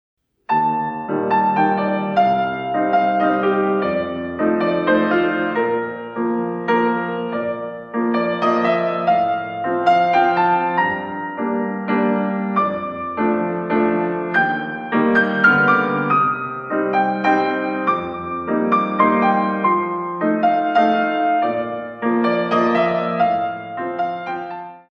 In 3
32 Counts
Rond de Jambe par Terre